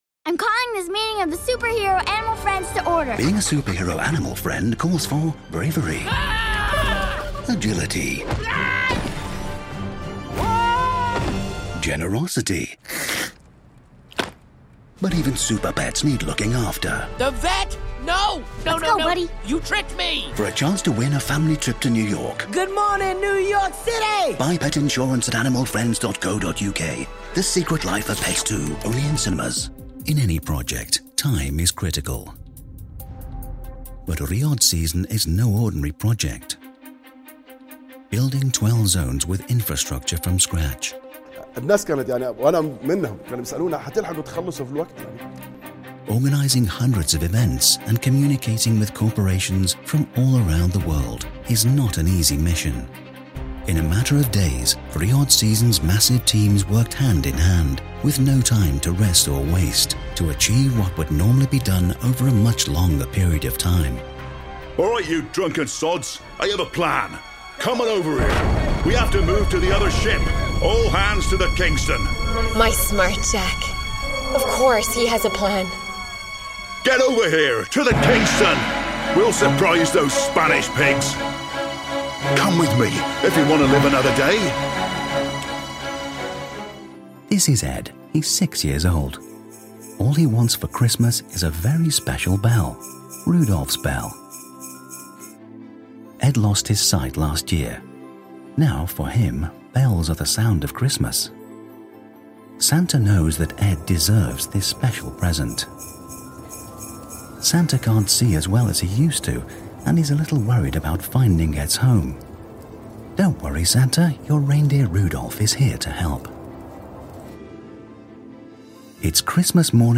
Male Voiceover Artist of the Year | COMPILATION